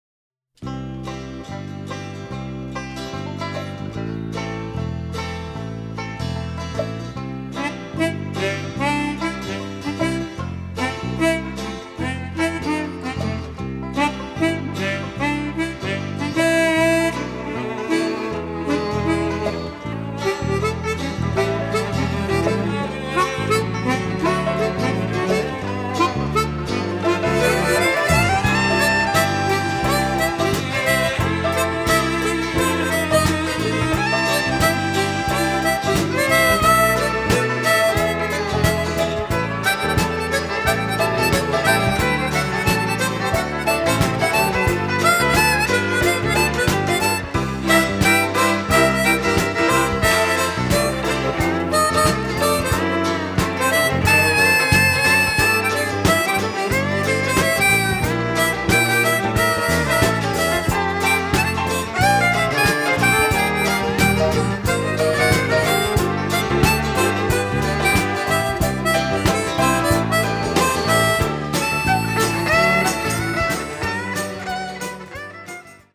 trumpeter
cellist
in a luminous and captivating style